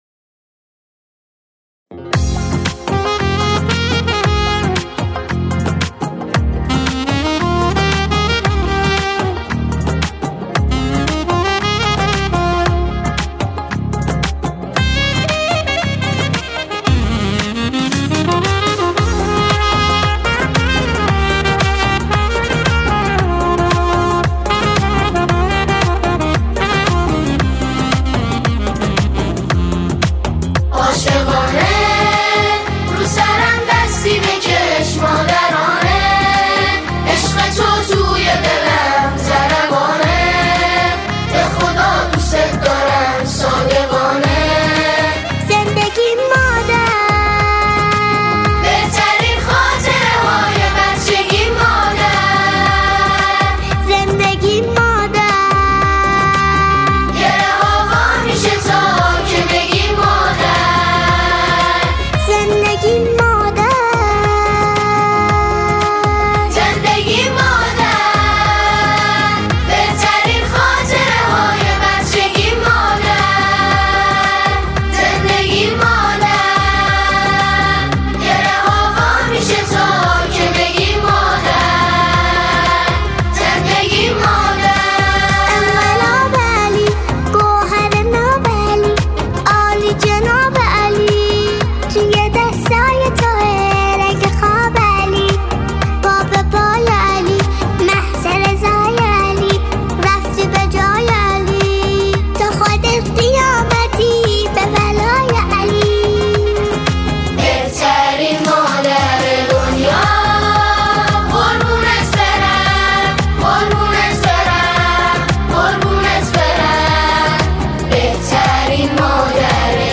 سرود ویژه ولادت حضرت فاطمه زهرا سلام الله علیها